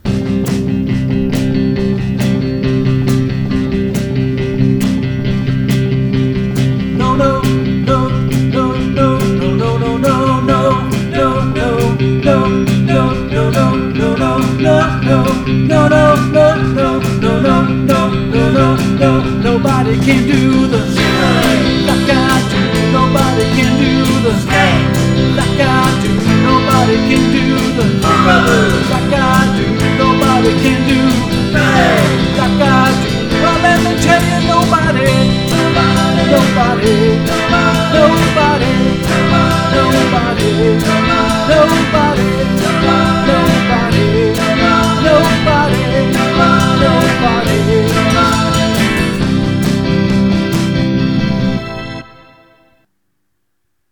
making a demo of my band, need advice
Your tracks are stereo 44100Hz mp3 which have a bit rate of 128Kbps which is too low : this means all the frequencies above 16KHz have been chopped off, ( have a look at “plot spectrum” in Analyze).